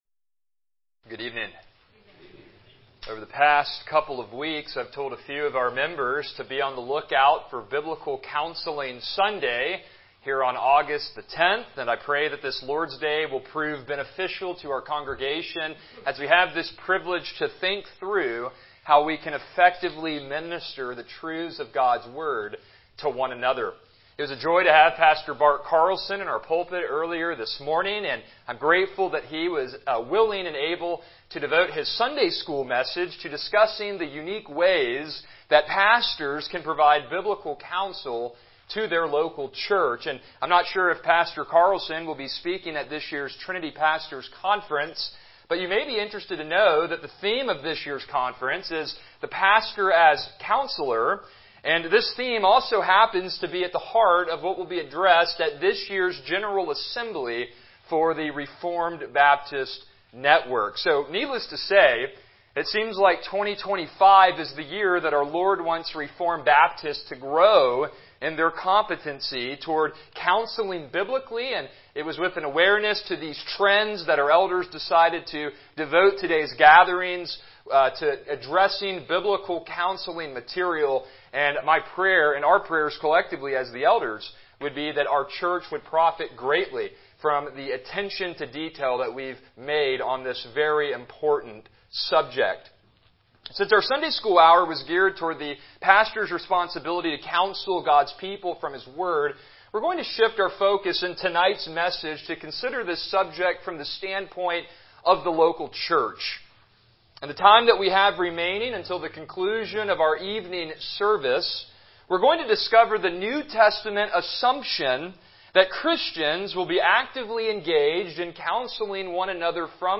Service Type: Evening Worship